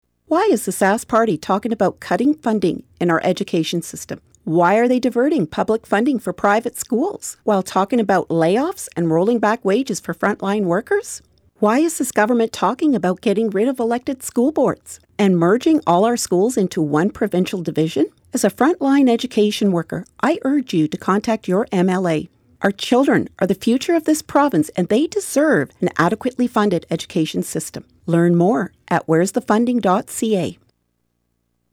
CUPE Education Workers’ Steering Committee launches radio ad campaign